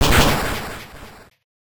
Battle damage super.ogg